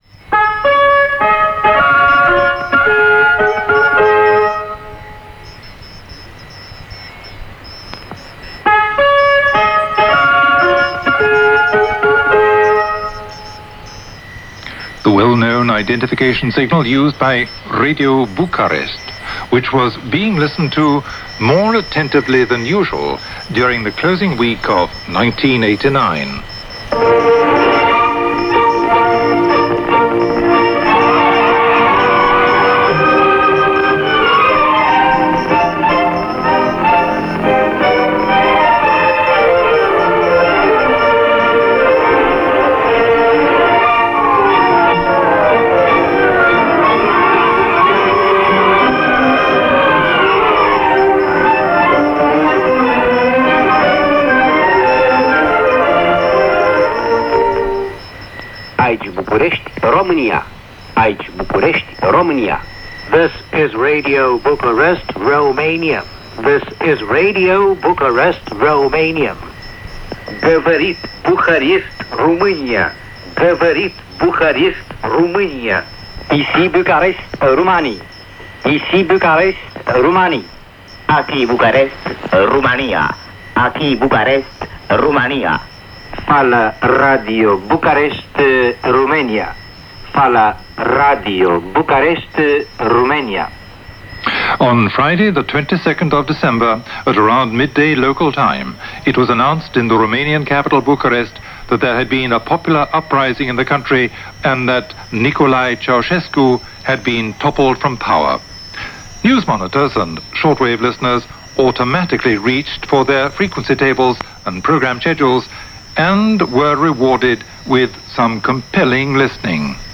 December 1989 - The Fire burned brightly in Bucharest - Revolution In Bulgaria - Austrian Radio Documentary - Past Daily Reference Room.
Austrian-Shortwave-Panorama-Romanian-Revolution-1989.mp3